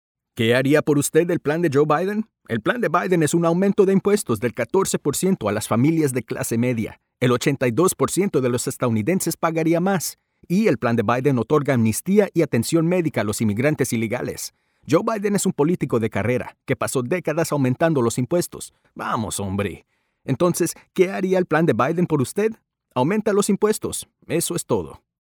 Spanish-speaking male voice actor
Spanish-Speaking Men, Political